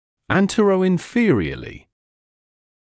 [ˌæntərəɪn’fɪərɪəlɪ][ˌэнтэрэин’фиэриэли]кпереди и книзу; вперед и вниз